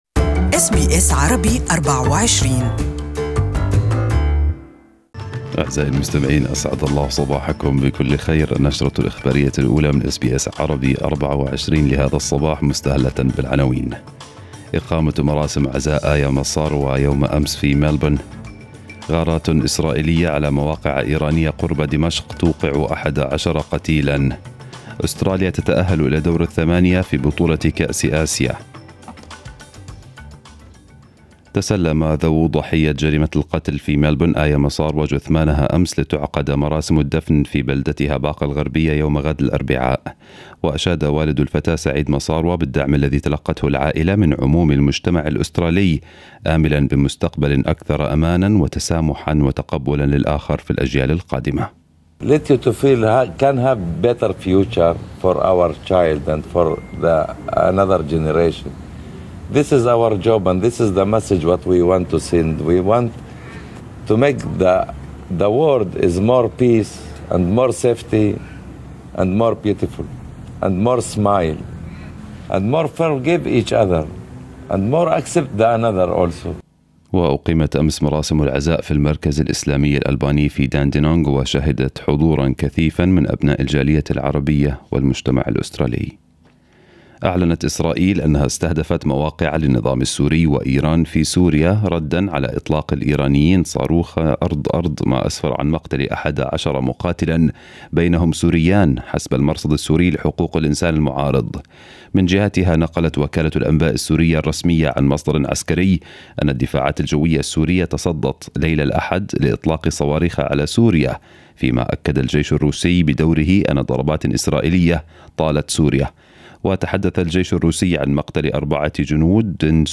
News bulletin in Arabic for the morning